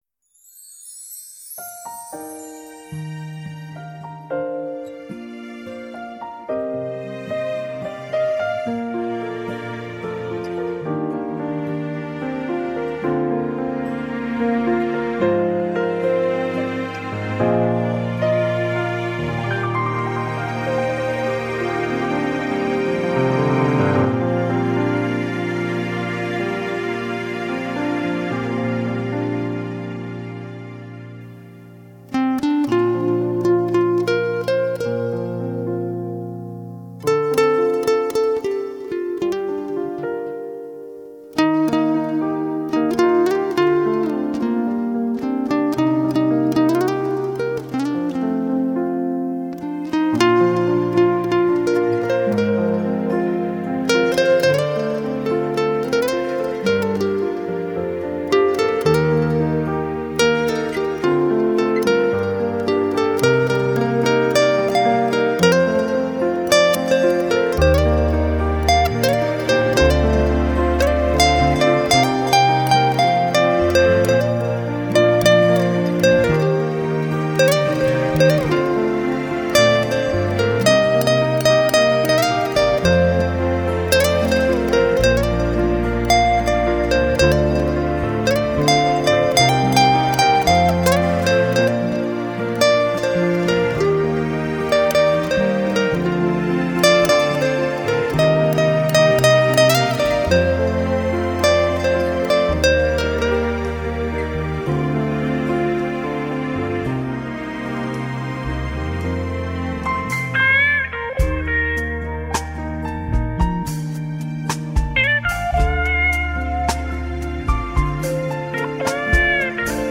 吉它
专辑音色清脆动人且温馨旖丽，不禁展示了精彩绝伦的空间感，而且带出吉他音箱共鸣声的无限通透。
就如在您身边演奏一般，自由、惬意、浪漫……